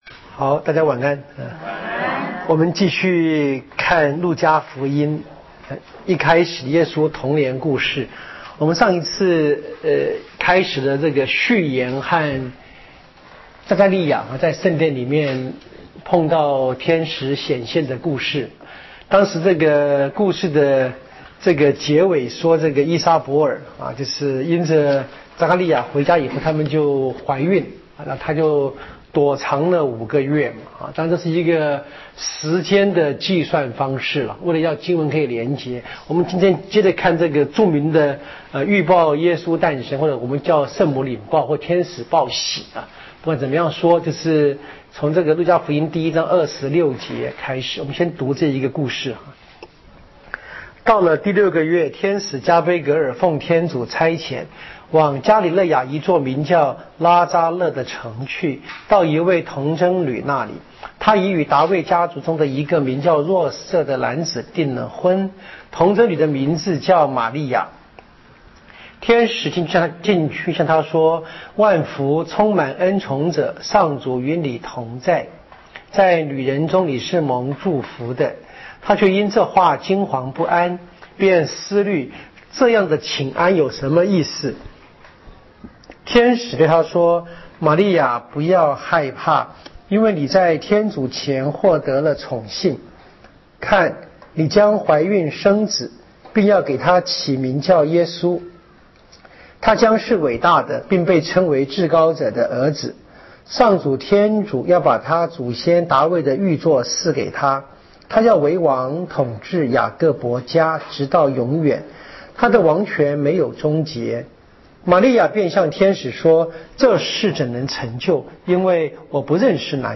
【圣经讲座】《路加福音》